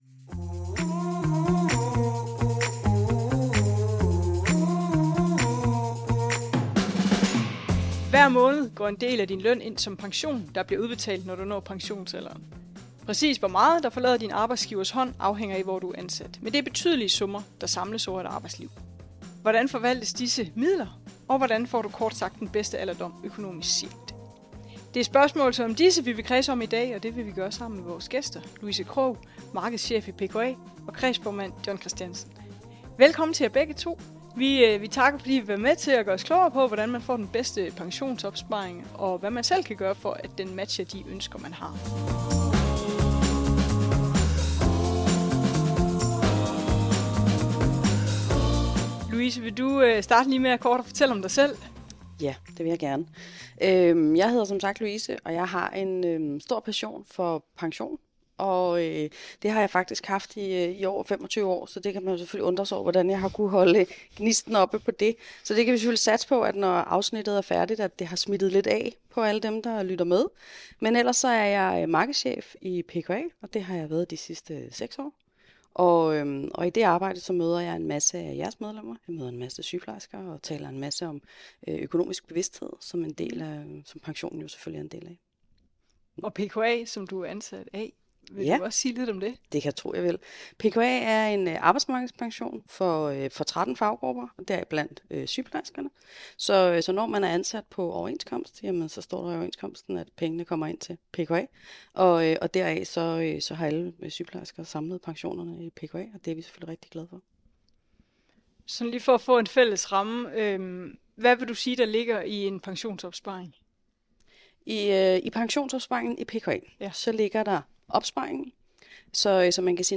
Vi er på besøg hos OUH til en snak med sygeplejerskerne